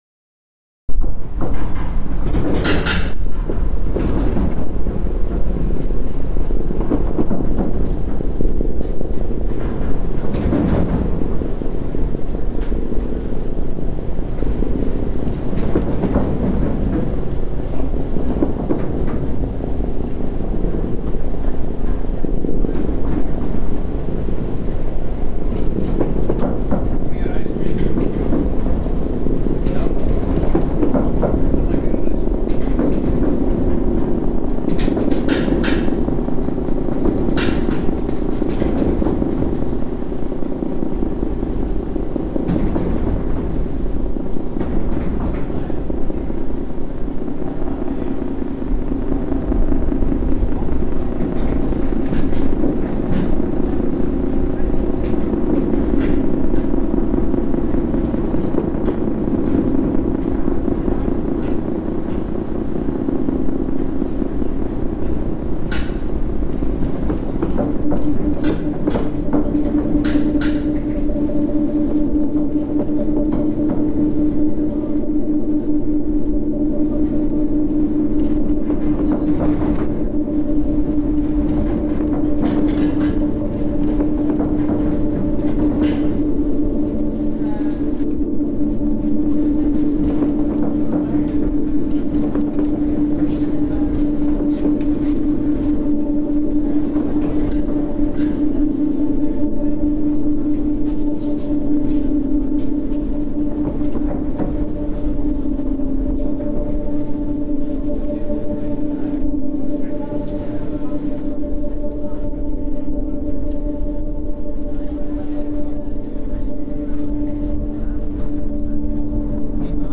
The architecture of the bridge and particularly the sound of crossing traffic was always fascinating to me; during a visit to London in 2018 I made field recordings, and soon after made this composition with it.
Hammersmith Bridge, London reimagined